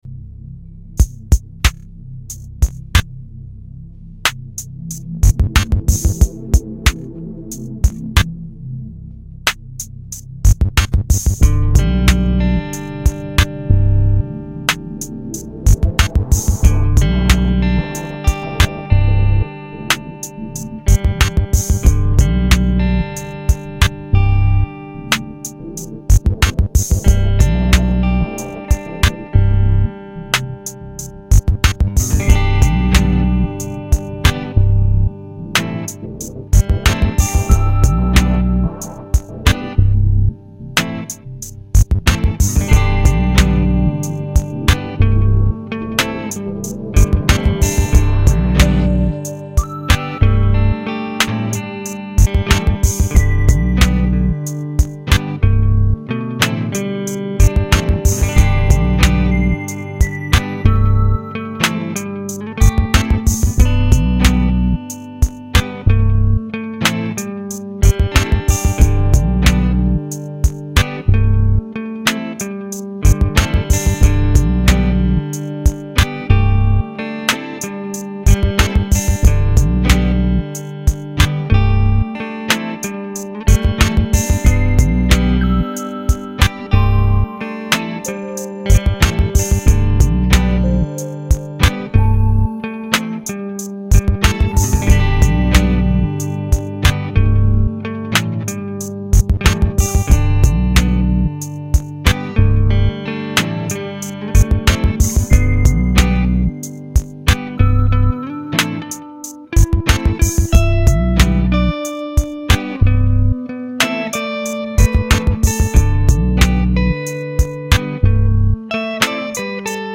Recorded and mastered on Micro BR - 26 May 2008
- Korg Kaossilator
- Fender Jaguar
My couch, Tokyo :)